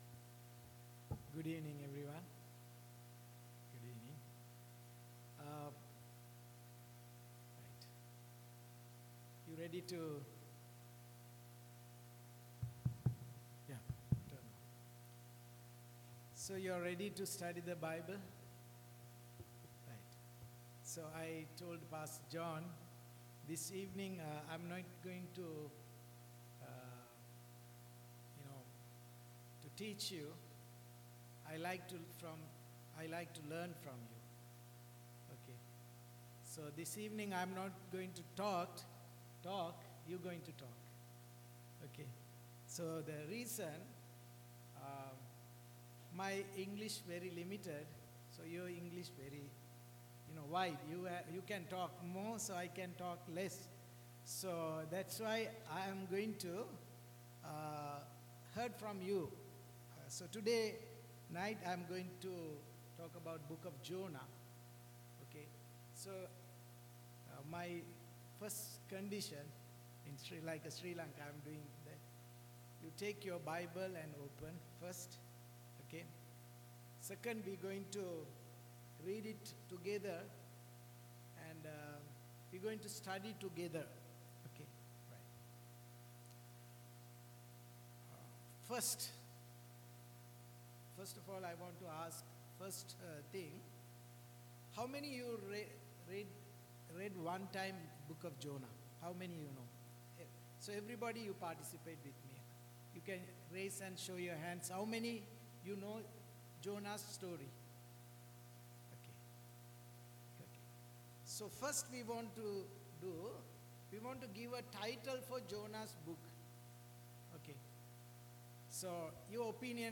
July-6-2023-Wednesday-Evening-Service.mp3